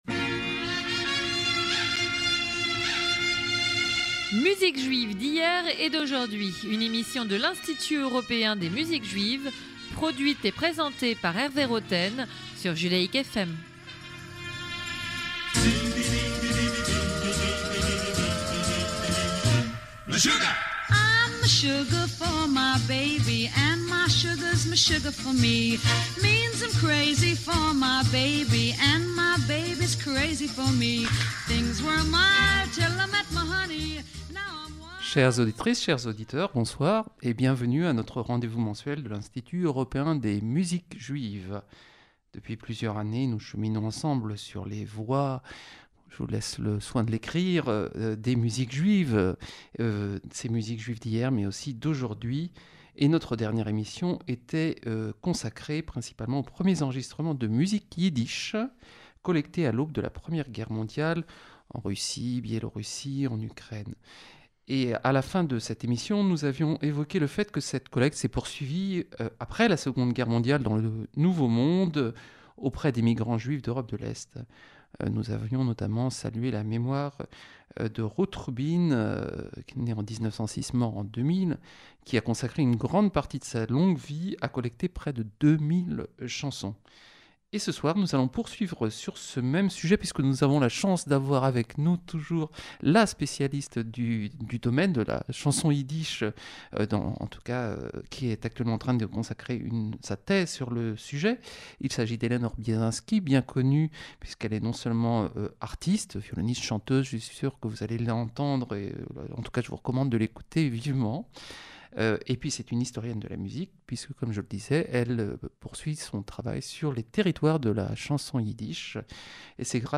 La segunda parte de este programa utilizará numerosos ejemplos musicales para mostrarnos cómo los artistas de hoy utilizan la música de ayer para realizar sus propias creaciones.